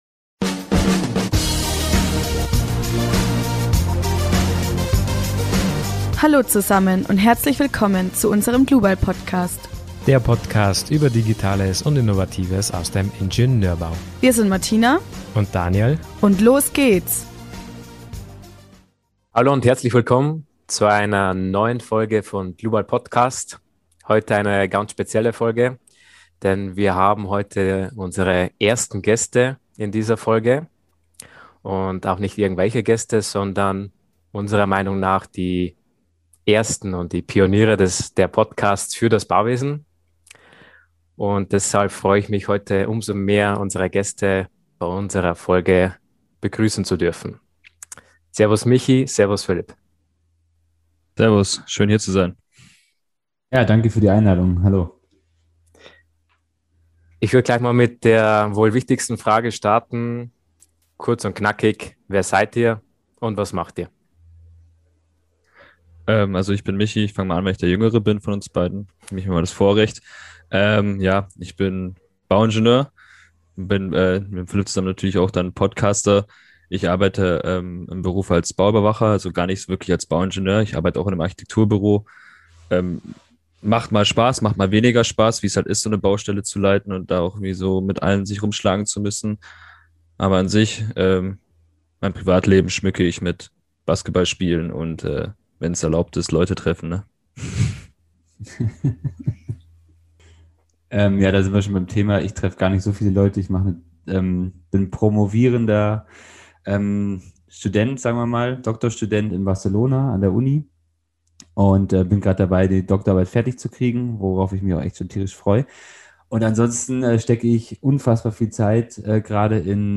Wir durften mit ihnen sprechen und sie als unsere ersten Interviewgäste begrüßen....